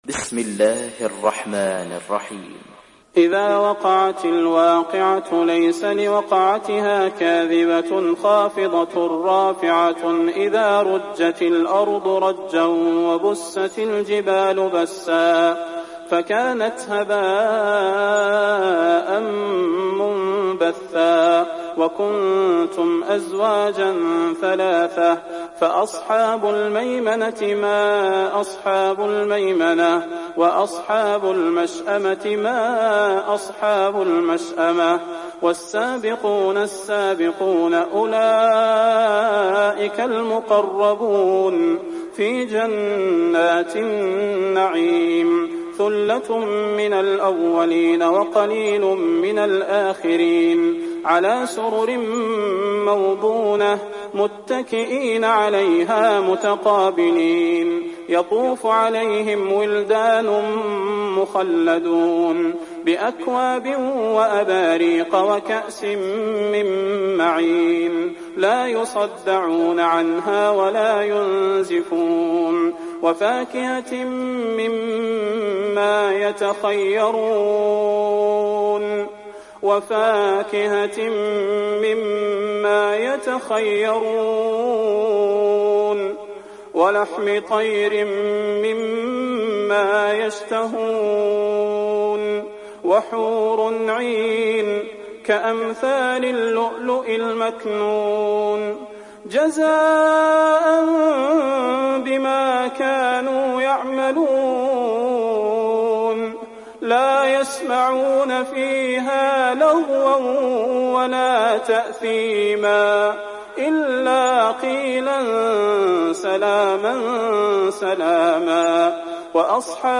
تحميل سورة الواقعة mp3 بصوت صلاح البدير برواية حفص عن عاصم, تحميل استماع القرآن الكريم على الجوال mp3 كاملا بروابط مباشرة وسريعة